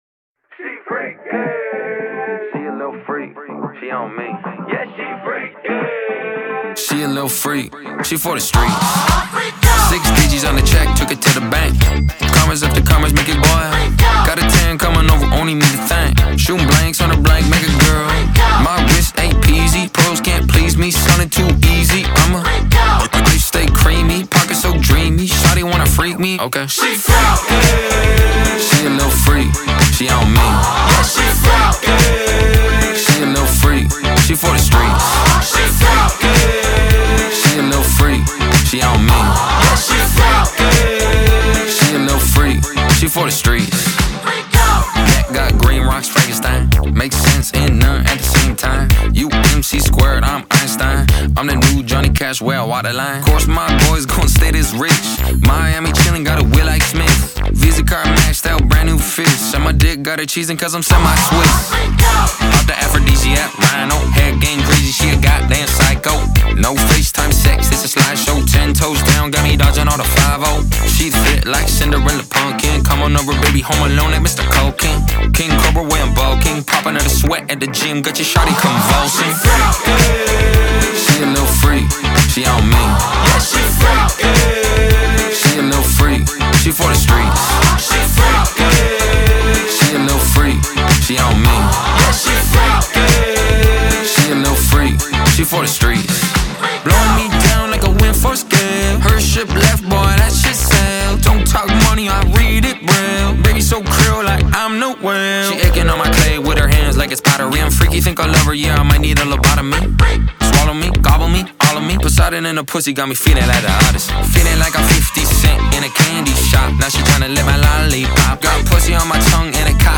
BPM110-110
Audio QualityPerfect (High Quality)
Rap song for StepMania, ITGmania, Project Outfox
Full Length Song (not arcade length cut)